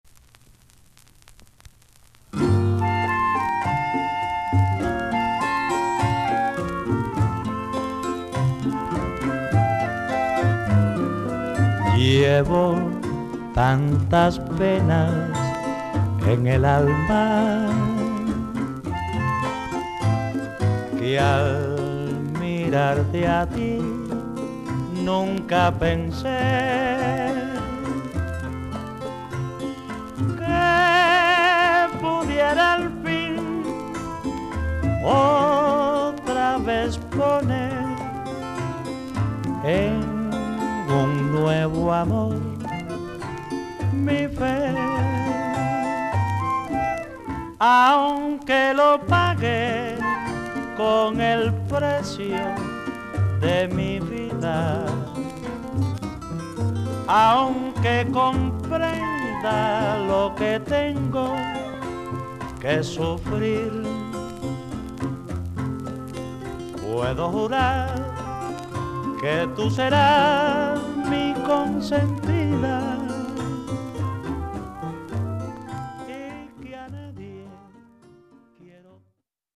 BOLERO